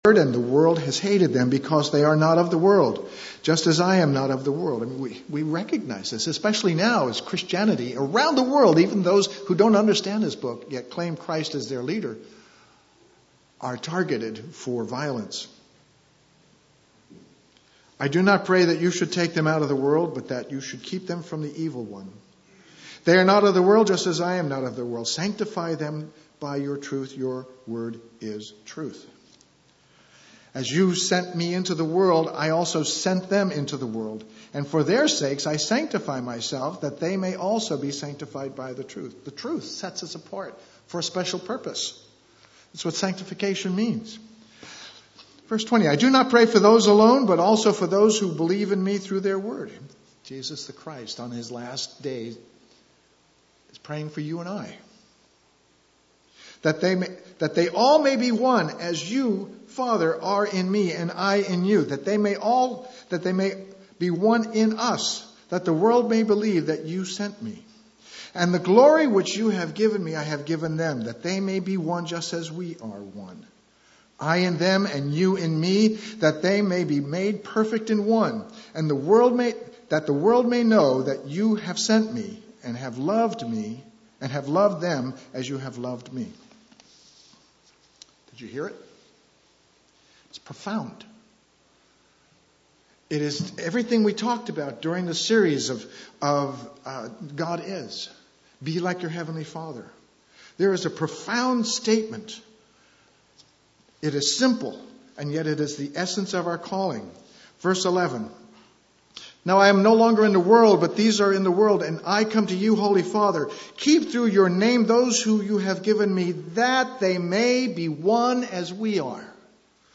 Jesus desires that we become one with God. That is not a partnership ... this requires total immersion in the nature of God.* Please note: due to technical difficulties, the first few minutes of the sermon were not recorded.
Given in Eureka, CA
UCG Sermon Studying the bible?